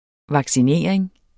Udtale [ vɑgsiˈneˀɐ̯eŋ ]